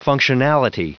Prononciation du mot functionality en anglais (fichier audio)
Prononciation du mot : functionality